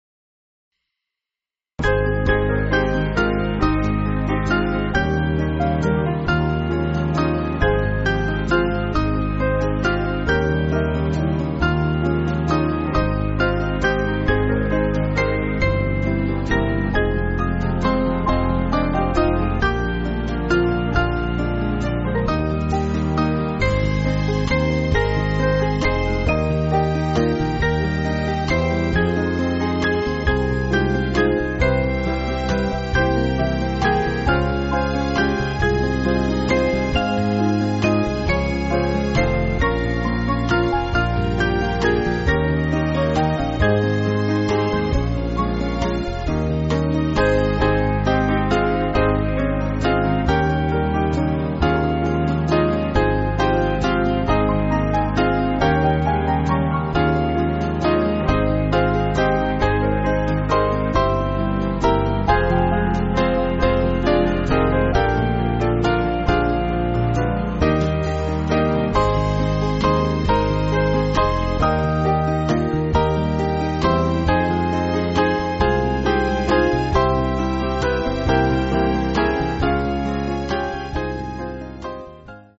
Small Band
(CM)   4/Ab